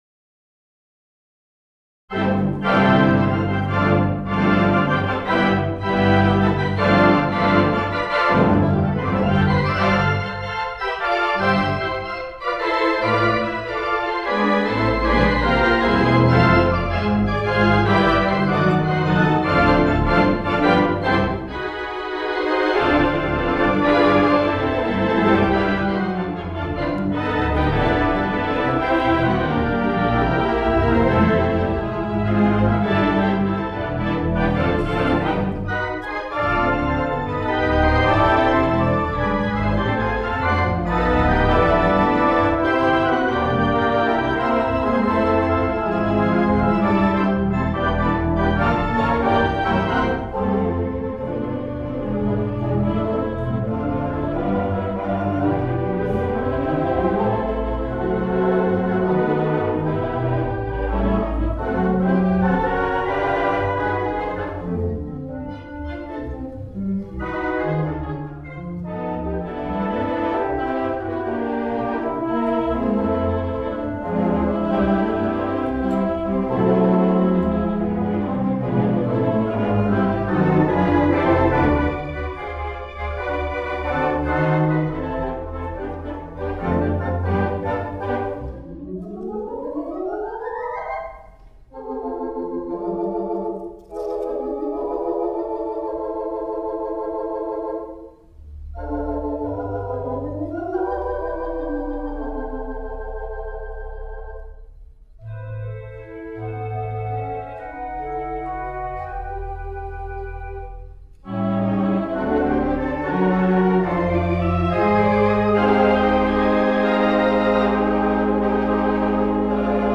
3/16 Mighty WurliTzer Theatre Pipe Organ